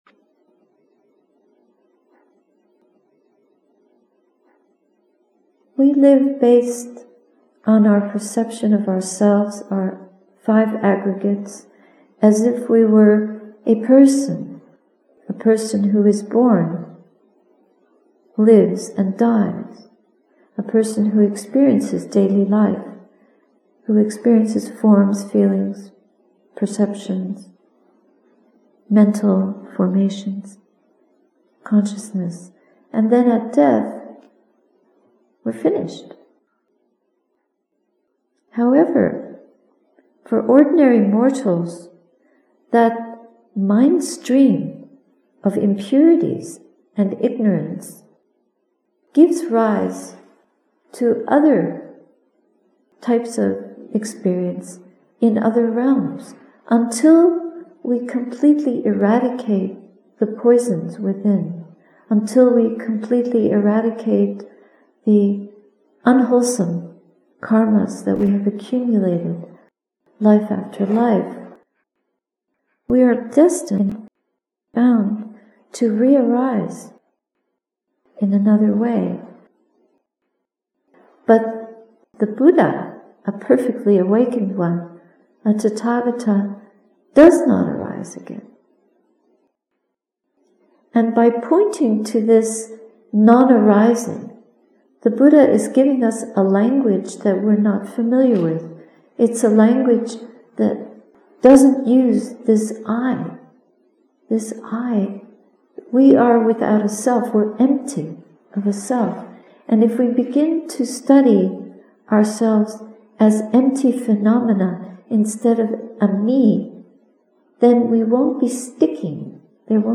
Breakfast reflections, Sati Saraniya Hermitage, Nov 2018